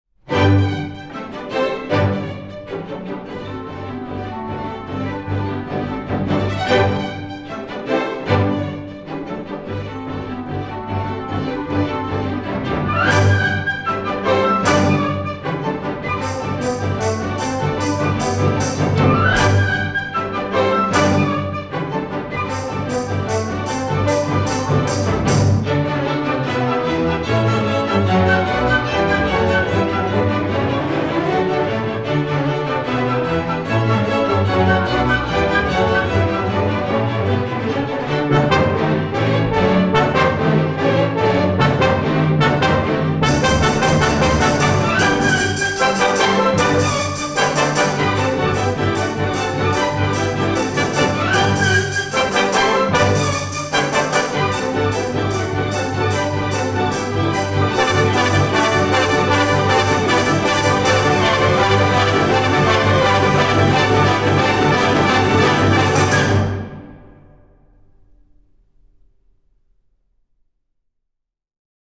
Classical and Jazz/ released October 8, 2013 on